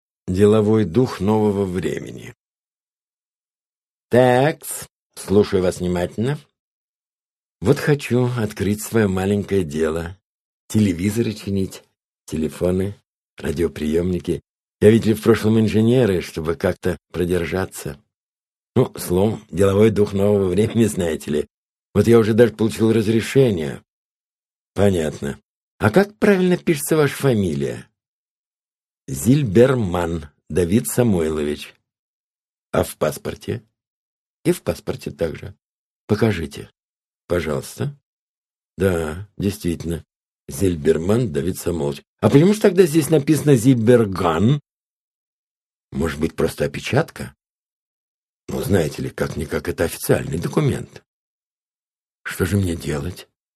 Aудиокнига Рассказы Автор Владимир Кунин Читает аудиокнигу Владимир Кунин.